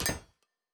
Fantasy Interface Sounds
Blacksmith 02.wav